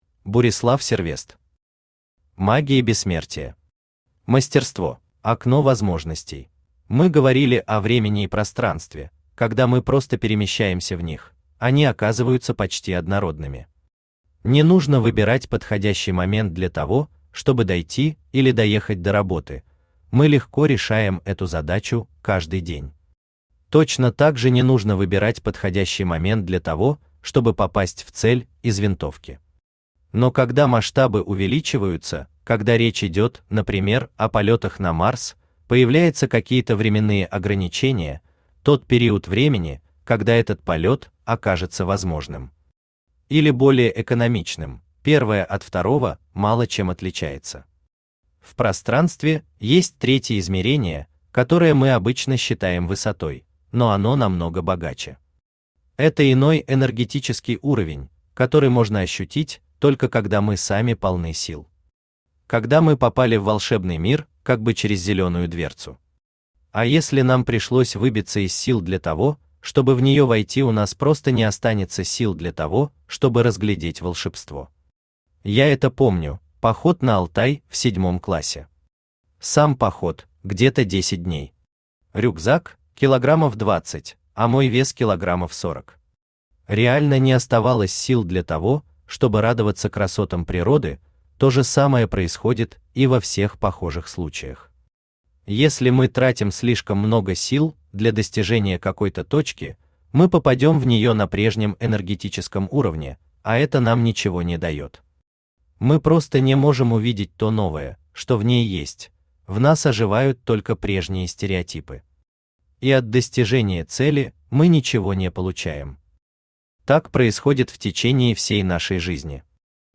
Аудиокнига Магия Бессмертия. Мастерство | Библиотека аудиокниг
Aудиокнига Магия Бессмертия. Мастерство Автор Бурислав Сервест Читает аудиокнигу Авточтец ЛитРес.